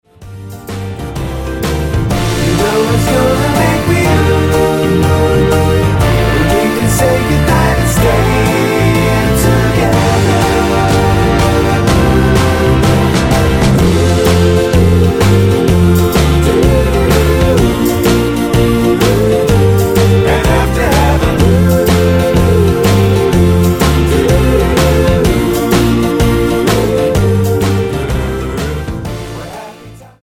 Tonart:F mit Chor